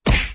铲子狼牙棒打人.mp3